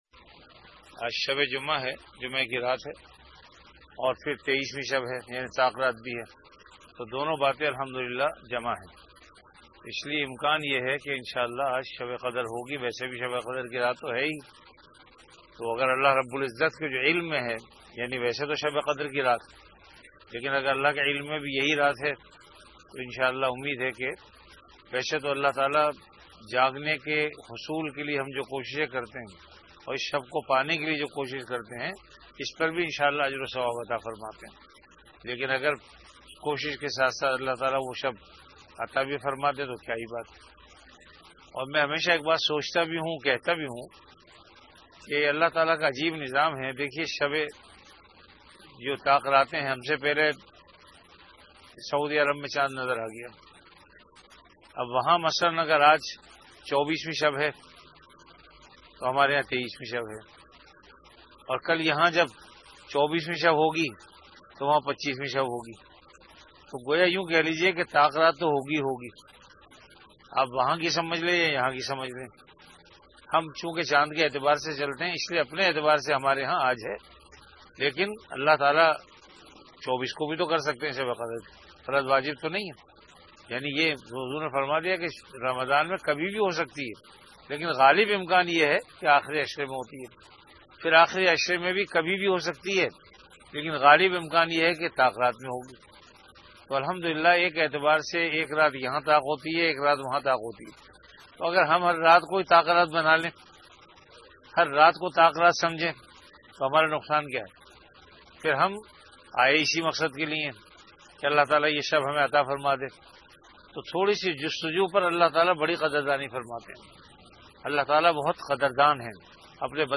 Ramadan - Etikaaf Bayanat · Jamia Masjid Bait-ul-Mukkaram, Karachi